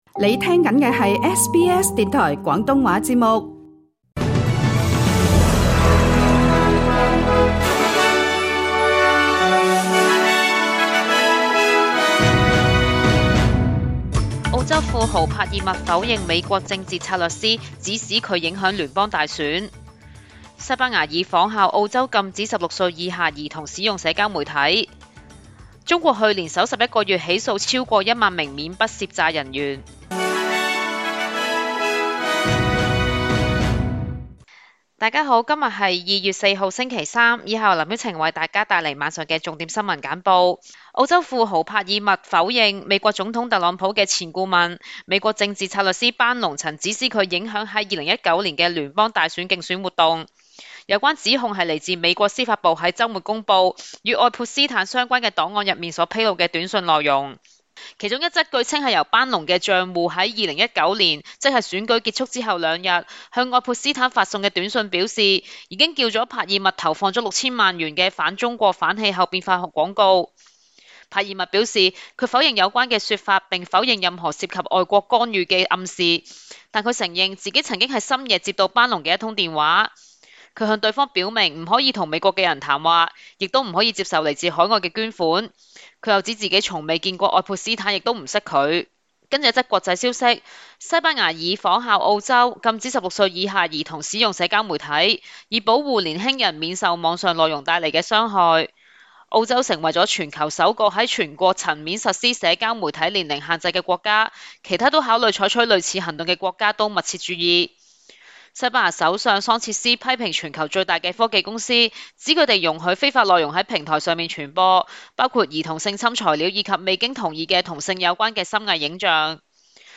SBS晚間新聞 (2026 年 2 月 4 日)
請收聽本台為大家準備的每日重點新聞簡報。